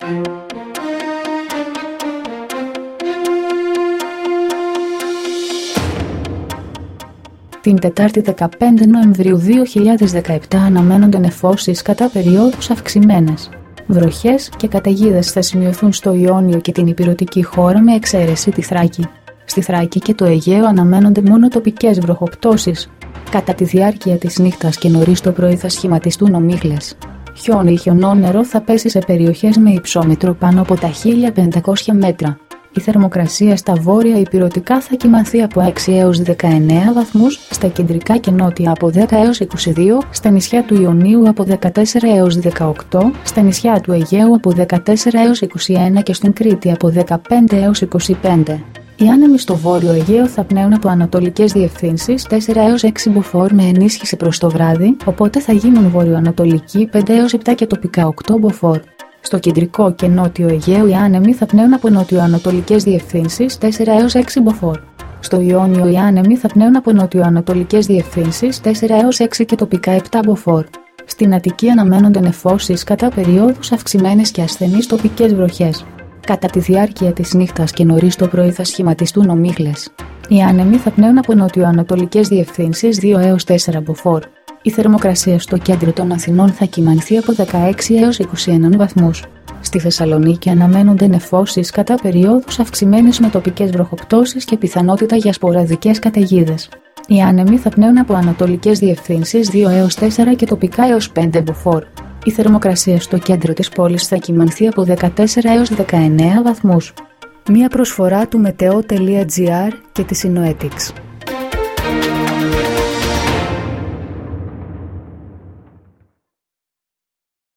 dailyforecast-8.mp3